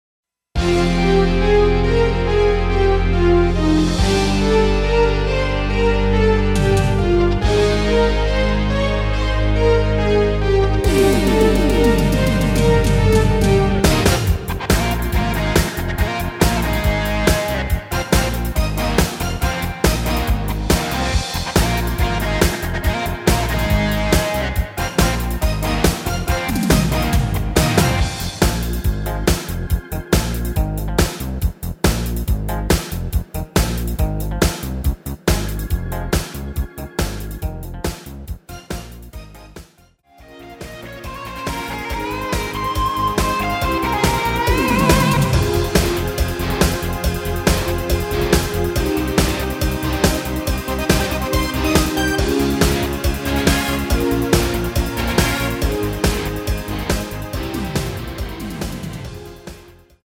Fm
◈ 곡명 옆 (-1)은 반음 내림, (+1)은 반음 올림 입니다.
앞부분30초, 뒷부분30초씩 편집해서 올려 드리고 있습니다.
중간에 음이 끈어지고 다시 나오는 이유는